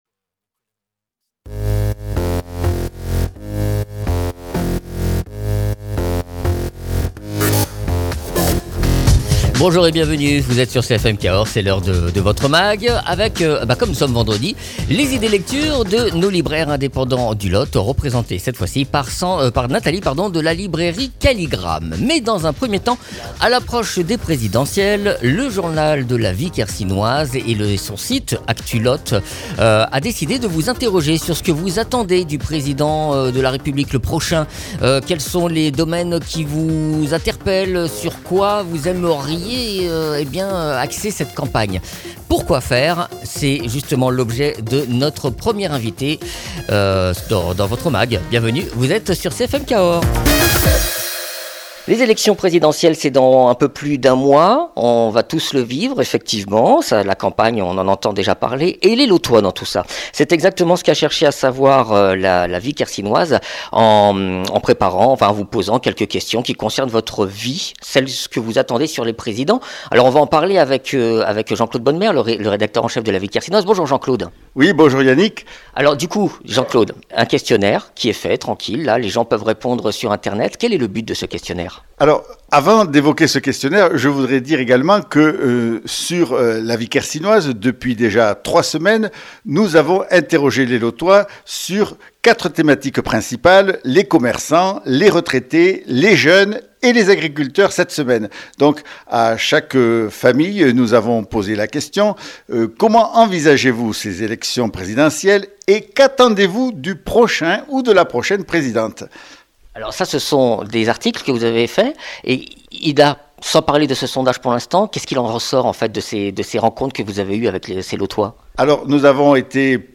Mags